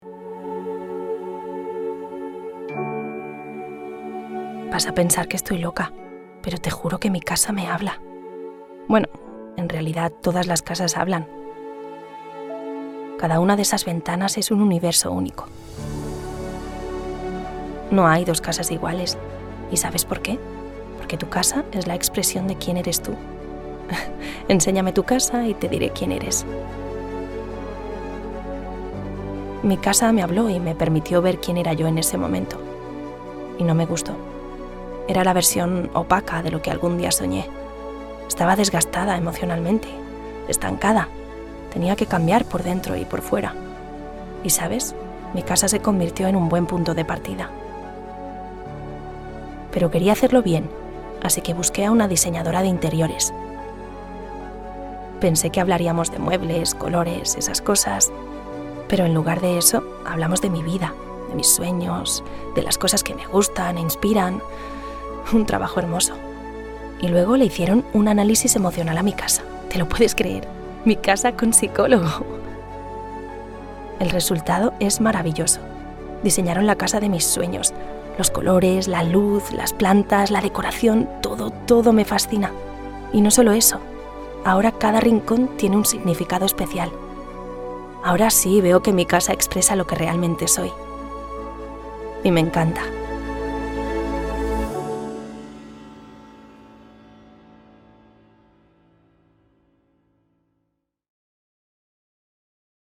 Spanish - Spain (Castilian)
Yng Adult (18-29), Adult (30-50)
I have a young, natural, friendly and fresh Spanish female voice.
I can use a very conversational tone, perfect for social media or TV spots.
Emotional/Natural Online Video